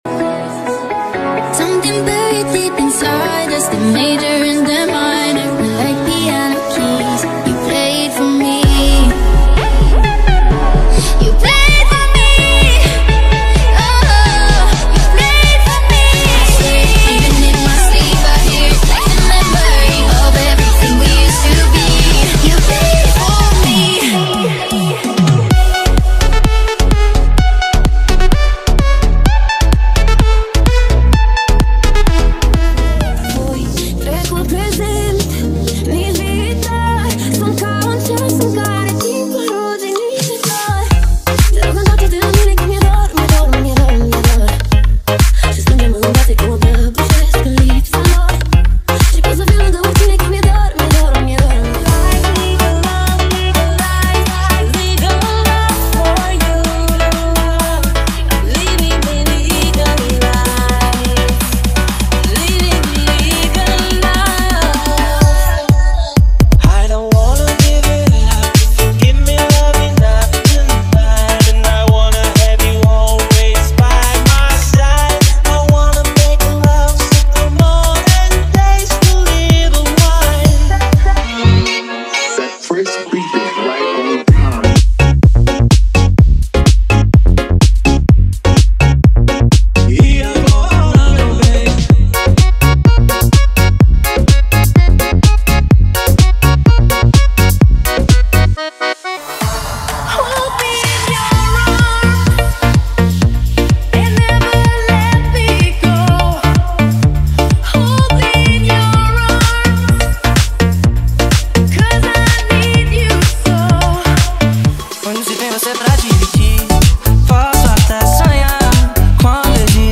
• Dance Comercial = 50 Músicas
• Sem Vinhetas
• Em Alta Qualidade